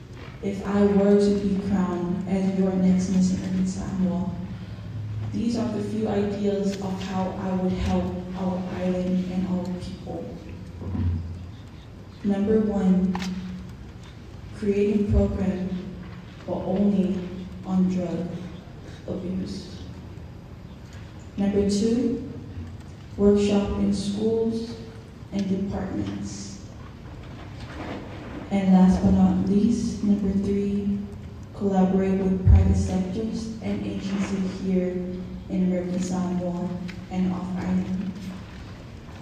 They started with prepared presentations on their selected topics, and then they answered judges questions.
Here are excerpts from the four contestants on the topics of education, drugs, environment and tourism.